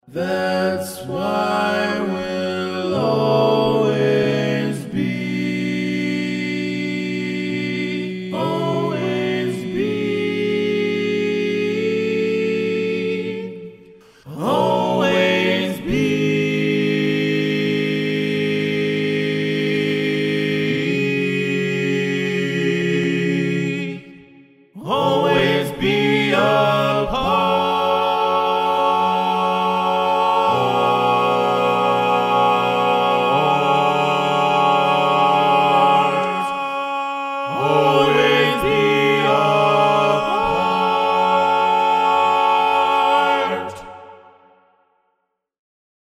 Key written in: F# Minor
Type: Barbershop